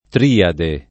triade [ tr & ade ] s. f.